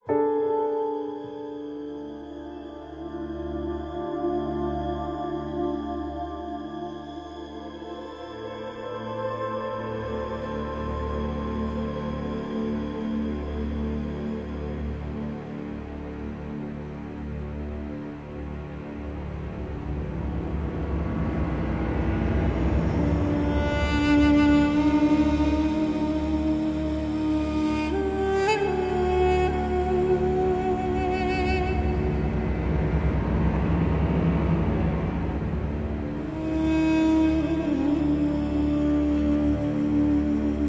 a cool, jazzy trip with a sci-fi chaser